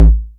kick.wav